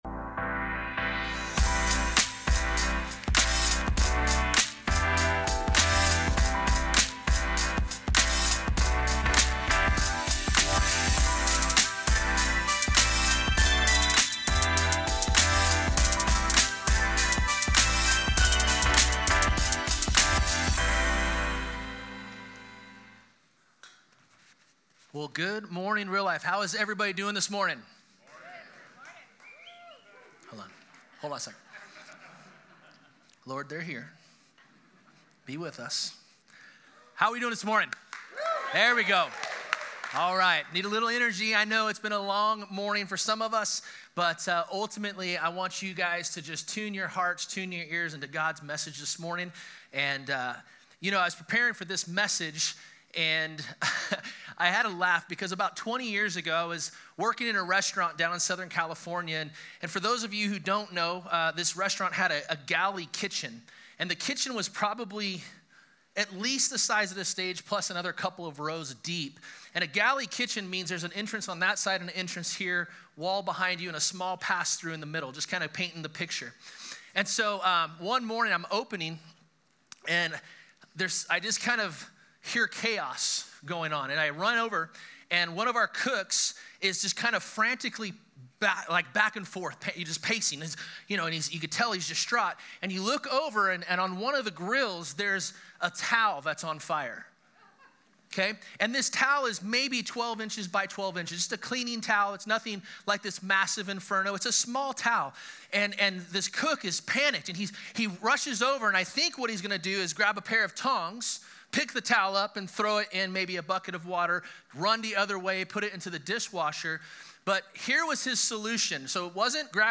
Hayden Campus
Sermon